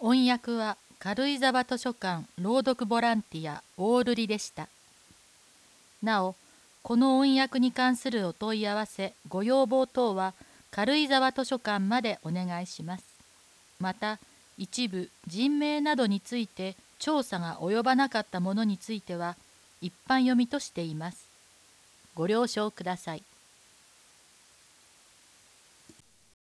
音声データ　軽井沢図書館朗読ボランティア「オオルリ」による朗読です